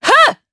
Seria-Vox_Attack4_jp.wav